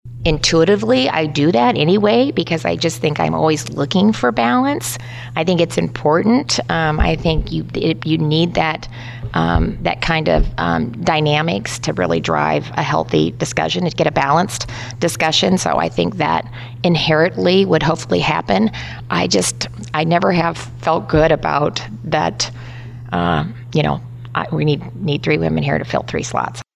Reynolds made her comments during an interview with Radio Iowa.